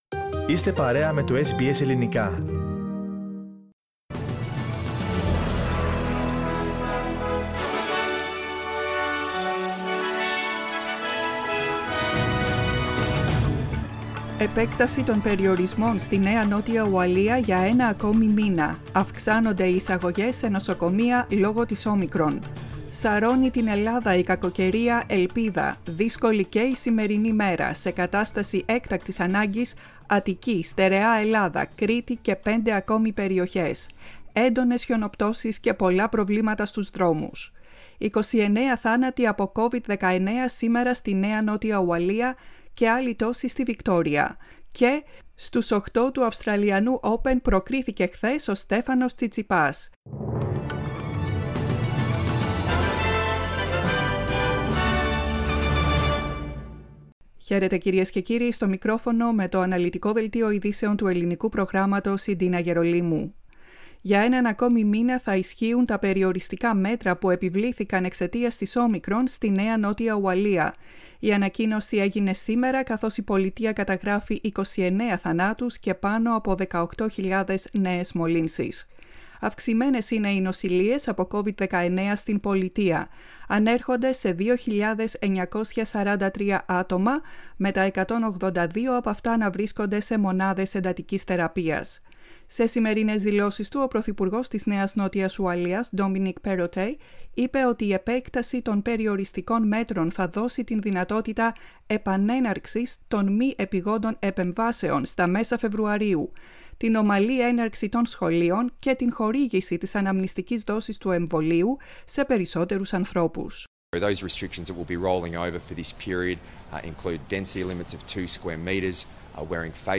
Listen to the main bulletin of the day from the Greek Program.